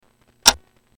Ticking sound